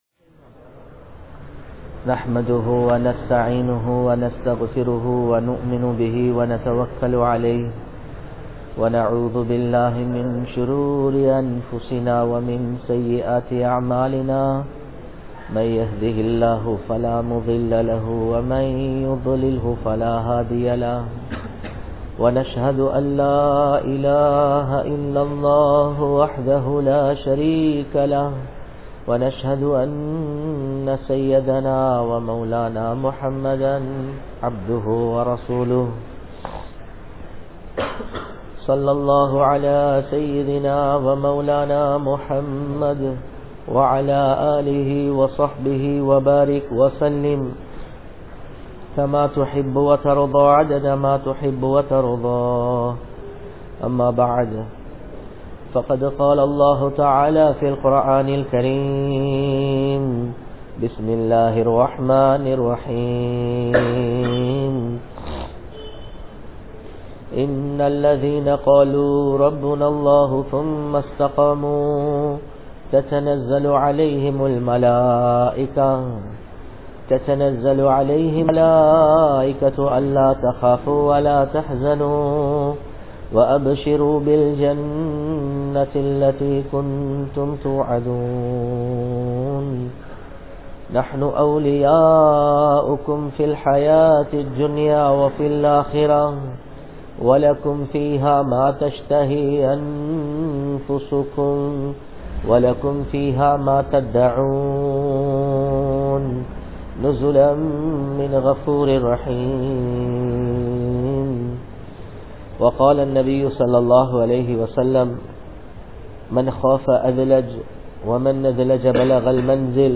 Vaalifaththin Perumathi (வாலிபத்தின் பெறுமதி) | Audio Bayans | All Ceylon Muslim Youth Community | Addalaichenai
Grand Jumua Masjith